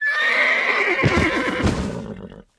horse_dead.wav